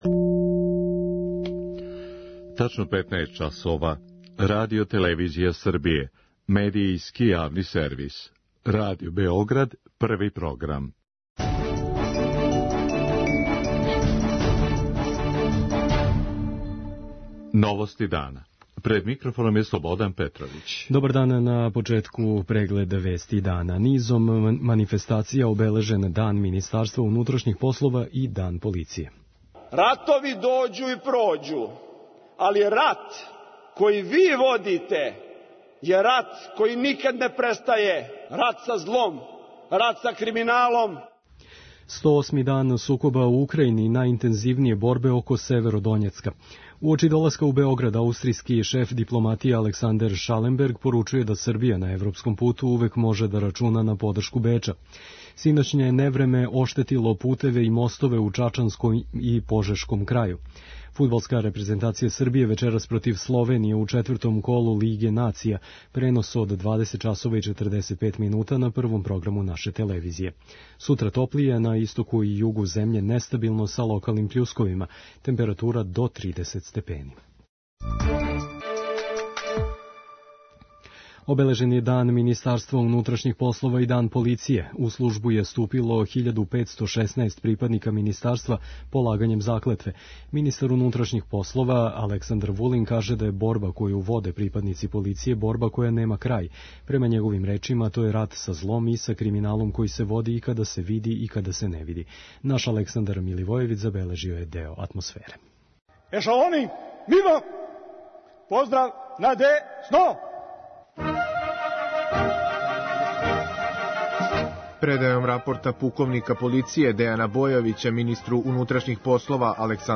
Позвао је све припаднике МУП-а да никада не изгубе част. преузми : 5.54 MB Новости дана Autor: Радио Београд 1 “Новости дана”, централна информативна емисија Првог програма Радио Београда емитује се од јесени 1958. године.